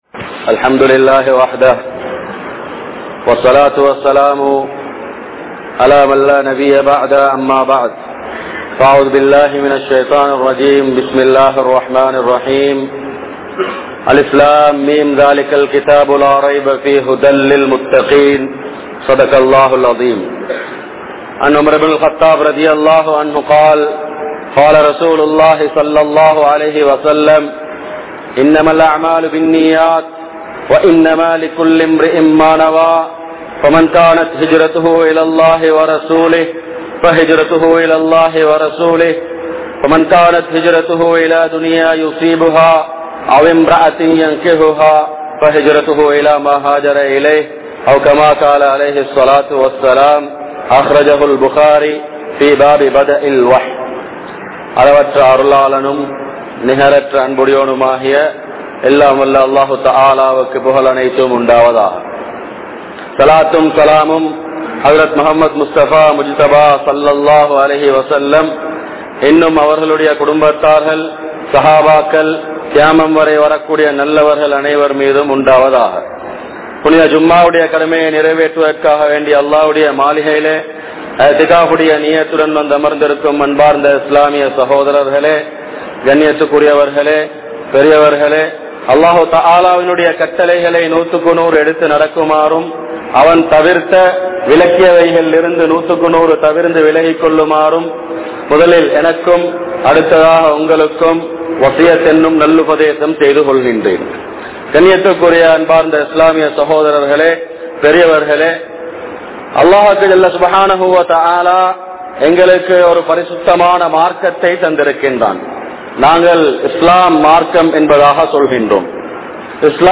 Good Qualities | Audio Bayans | All Ceylon Muslim Youth Community | Addalaichenai